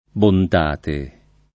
bontate [ bont # te ]